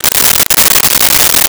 Wrapper Opened 01
Wrapper Opened 01.wav